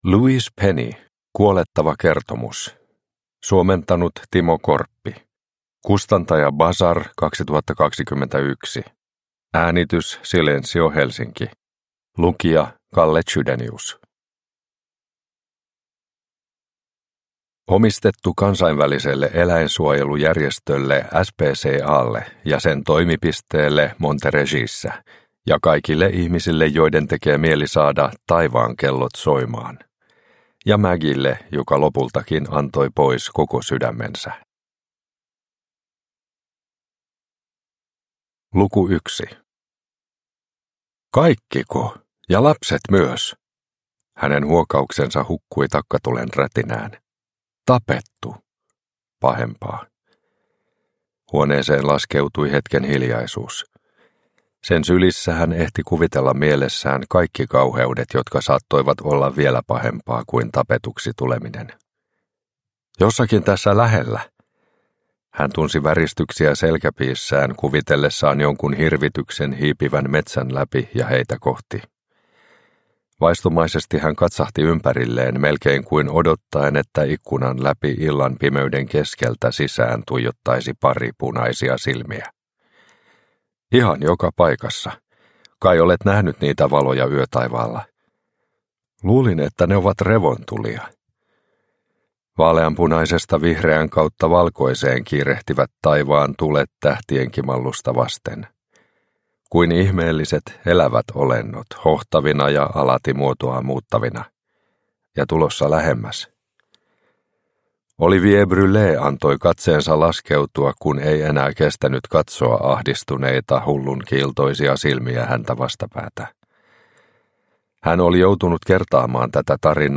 Kuolettava kertomus – Ljudbok – Laddas ner